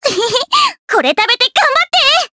voices / heroes / jp
May-Vox_Skill1_2_jp.wav